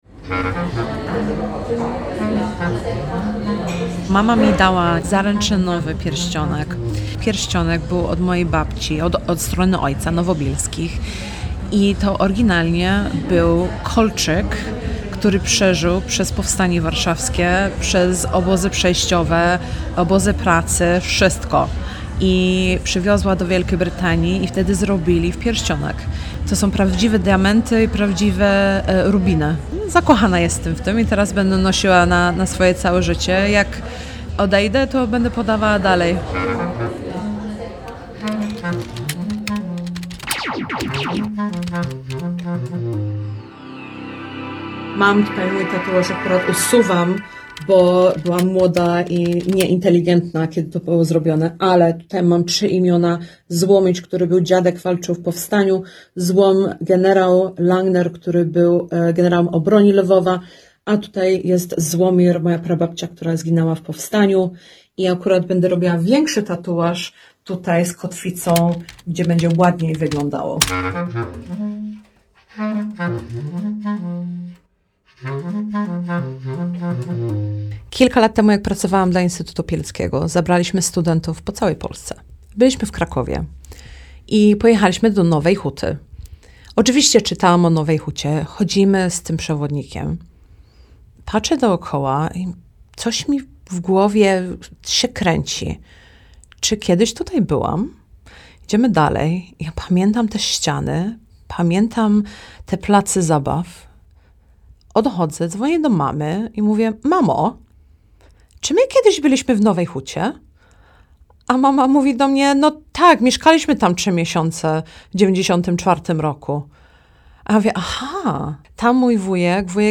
Reportaż w Radiu Kraków